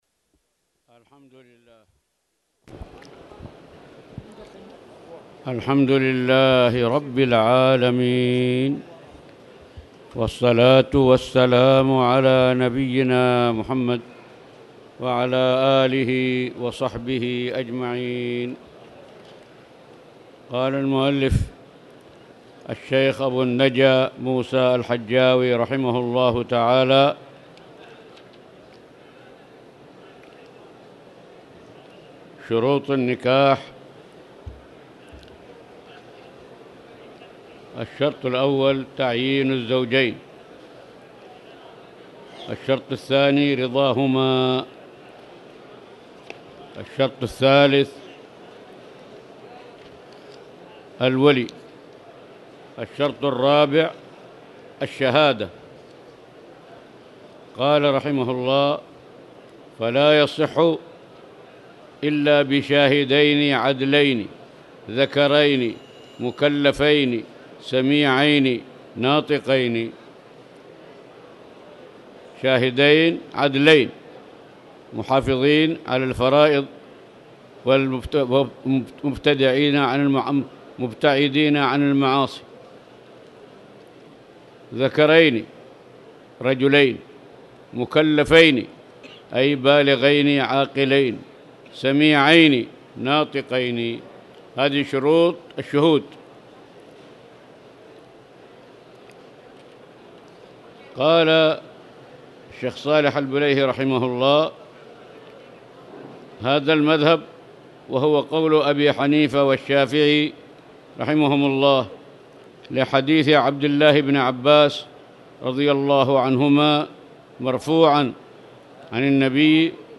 تاريخ النشر ٣ رمضان ١٤٣٧ المكان: المسجد الحرام الشيخ